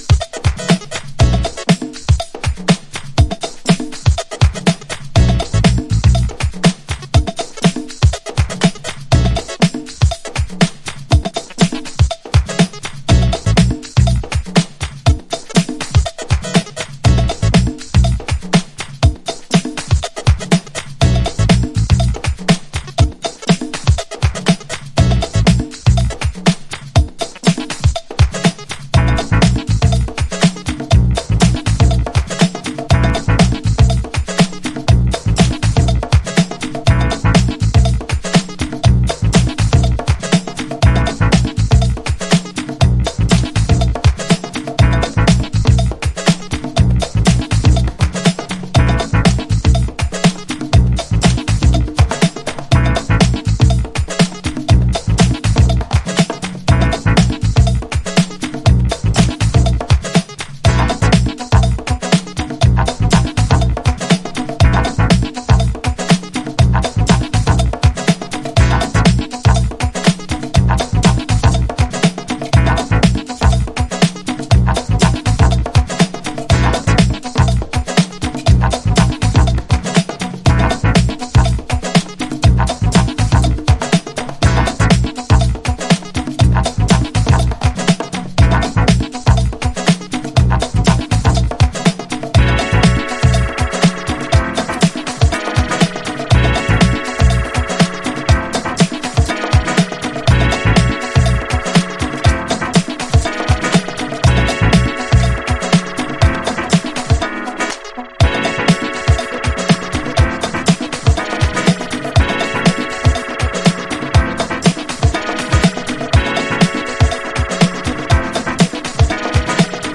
Alt Disco / Boogie
生を意識したブギービートシーケンス。